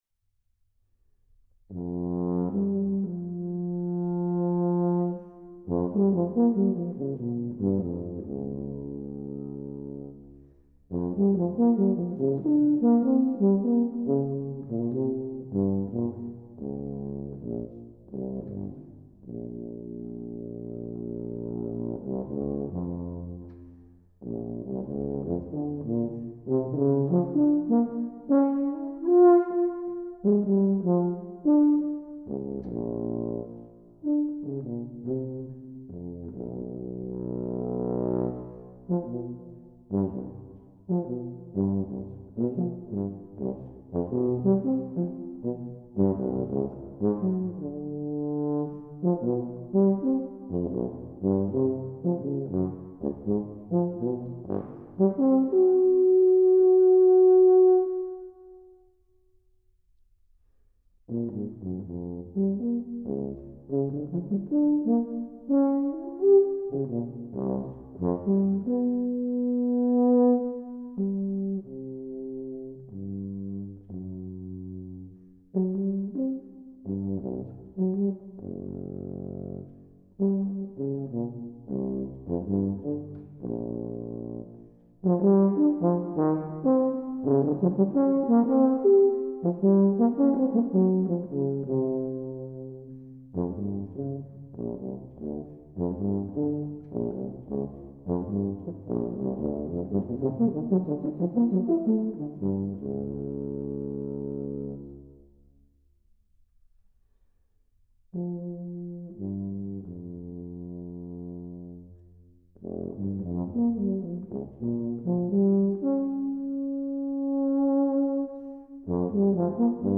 Solo
no acompañado
Tuba (Solo), Tuba mib (Solo), Tuba sib (Solo)